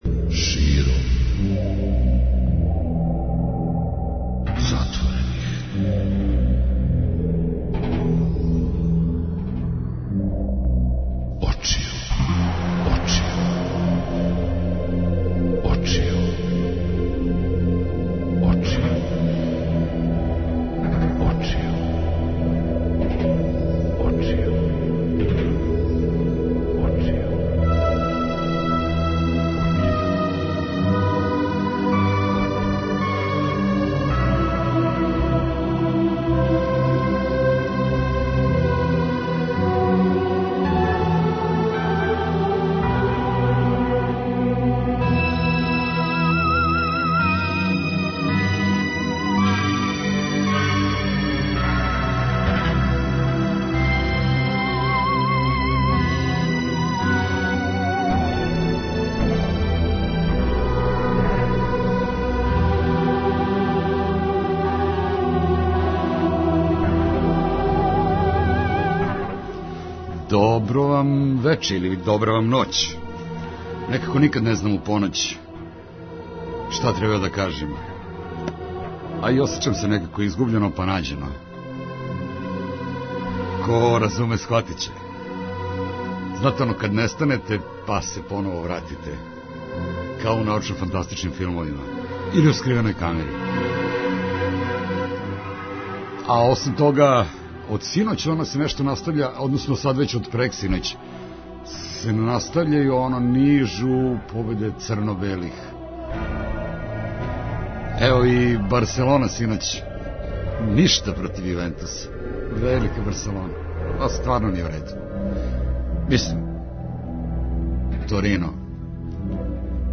Лига 202 и Куп 202 - спој добре рок музике, спортског узбуђења и навијачких страсти.